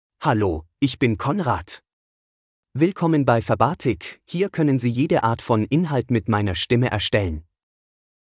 MaleGerman (Germany)
ConradMale German AI voice
Voice sample
Listen to Conrad's male German voice.
Male
Conrad delivers clear pronunciation with authentic Germany German intonation, making your content sound professionally produced.